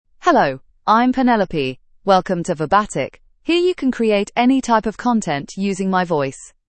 FemaleEnglish (United Kingdom)
PenelopeFemale English AI voice
Penelope is a female AI voice for English (United Kingdom).
Voice sample
Listen to Penelope's female English voice.
Female
Penelope delivers clear pronunciation with authentic United Kingdom English intonation, making your content sound professionally produced.